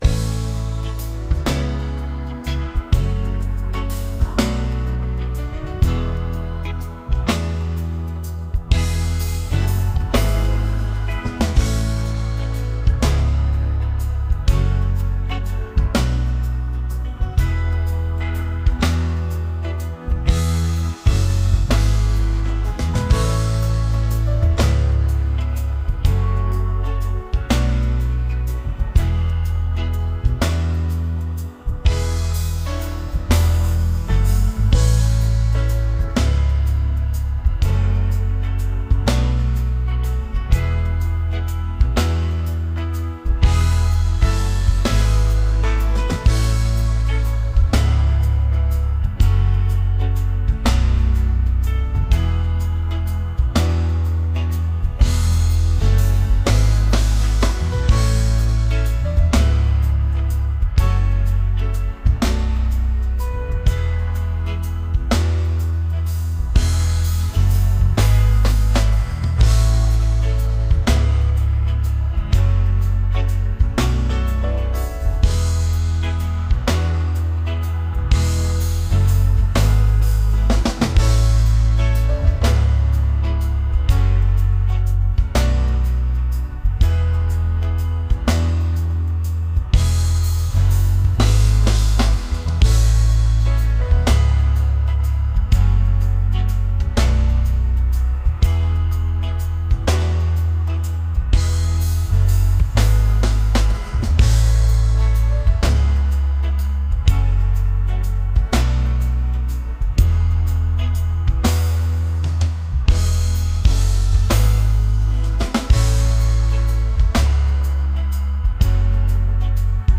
soulful